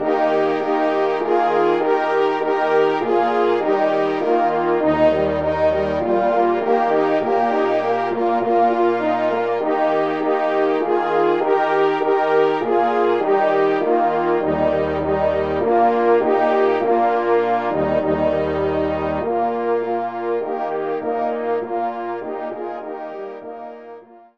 Genre :  Divertissement pour Tous les Cors
ENSEMBLE